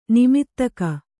♪ nimittaka